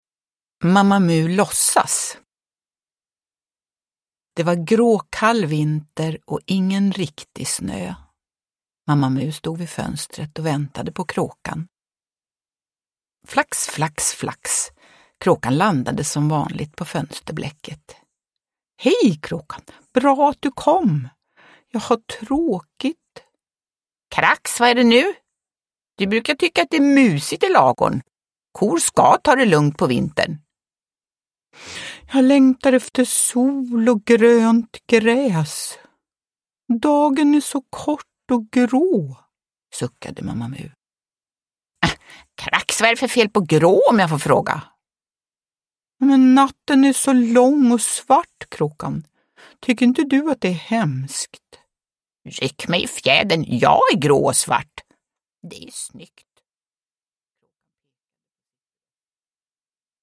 Mamma Mu låtsas – Ljudbok – Laddas ner
Uppläsare: Jujja Wieslander